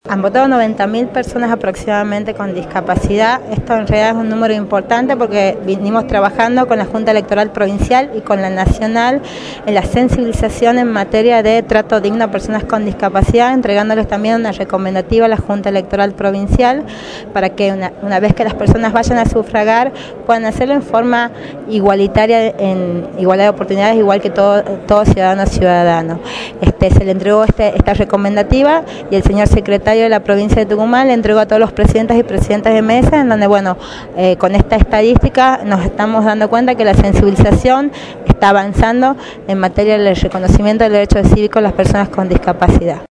Informe desde la Provincia de Tucumán